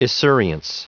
Prononciation du mot esurience en anglais (fichier audio)
Prononciation du mot : esurience